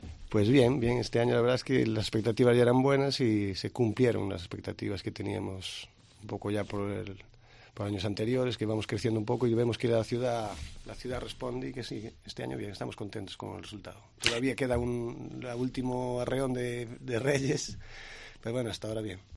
en los estudios de Cope Ourense